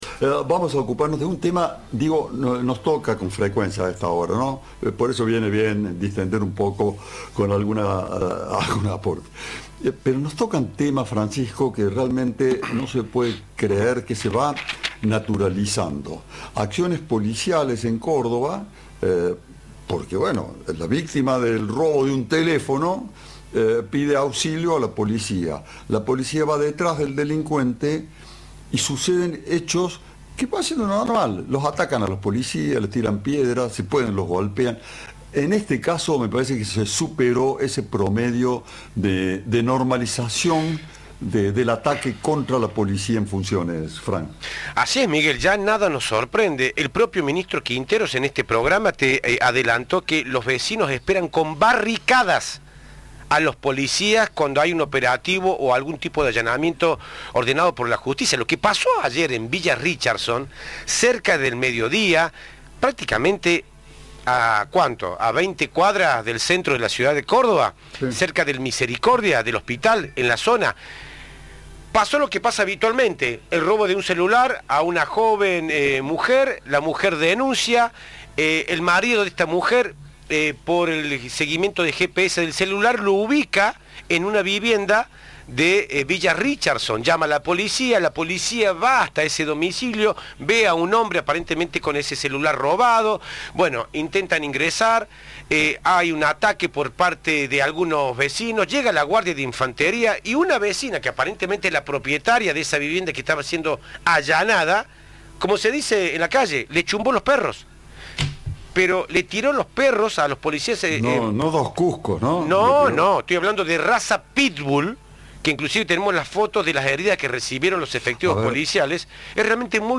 El fiscal de Instrucción, Raúl Garzón, comentó sobre el incidente en diálogo con Cadena 3: "Esto lamentablemente es una constante, lo que sí cada vez van innovando las formas de los ataques" y confirmó que están bien los policías involucrados.
Entrevista